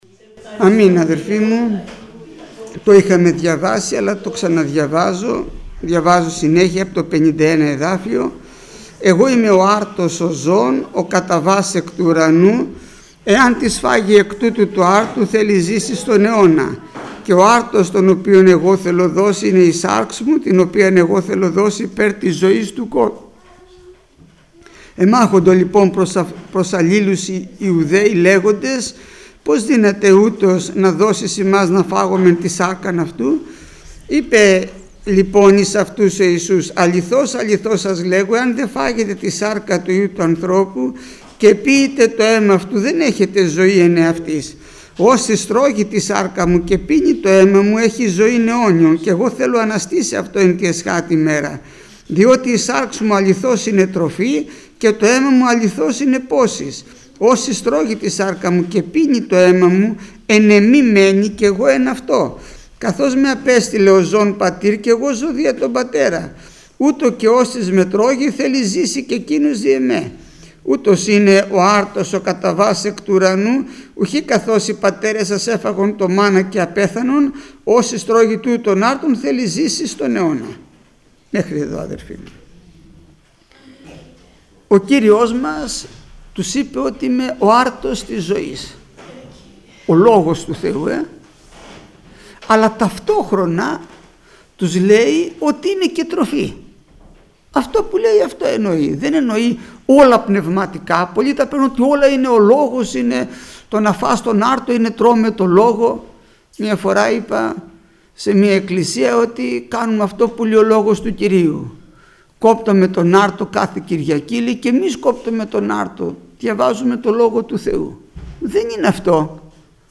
Μήνυμα πριν τη θεία κοινωνία
Μηνύματα Θείας Κοινωνίας